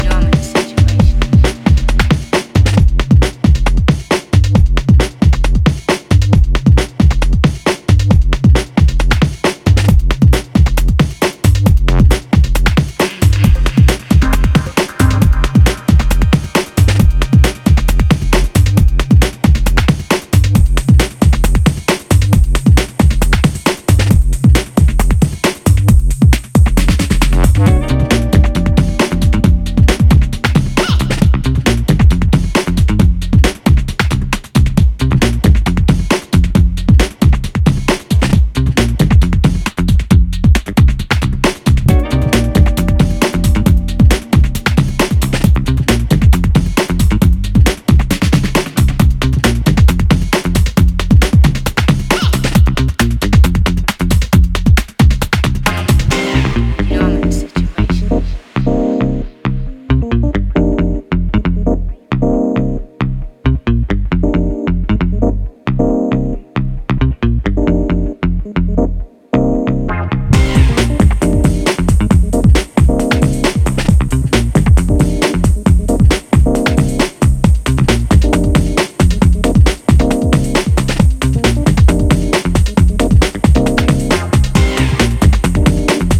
Ready for another round of lush, deep and unique sounds